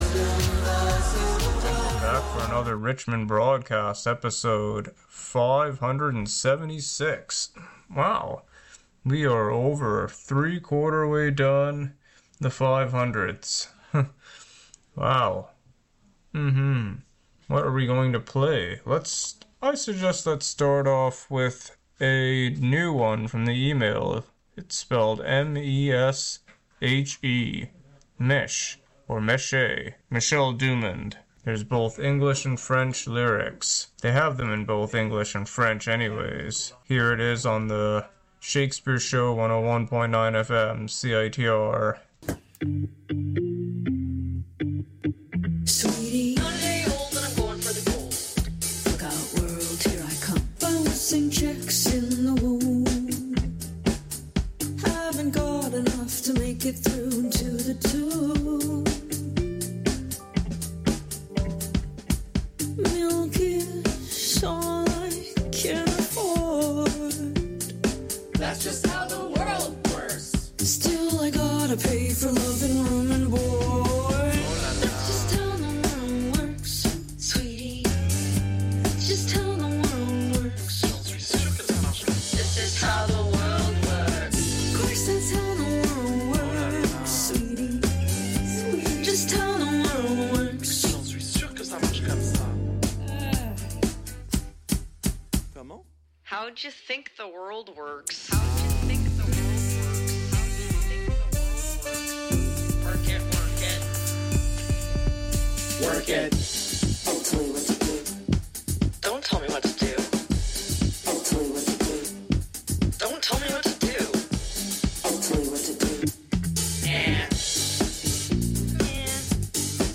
an eclectic mix of music